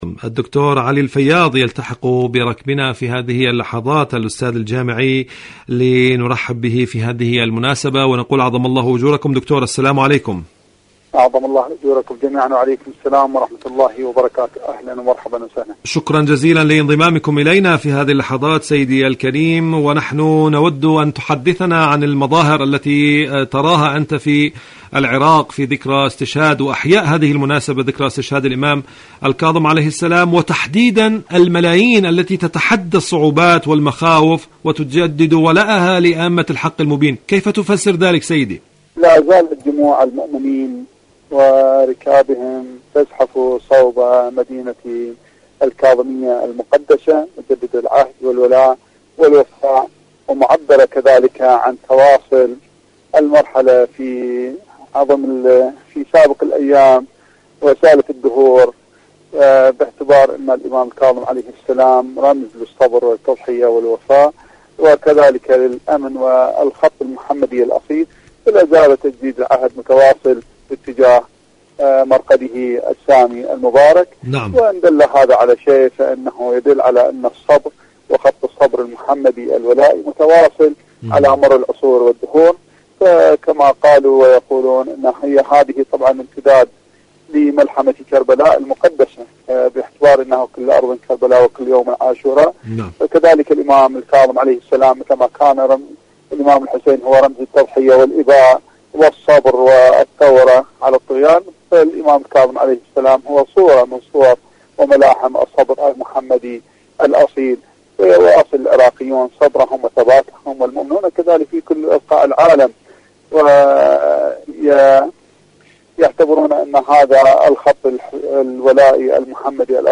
إذاعة طهران-عراق الرافدين: مقابلة إذاعية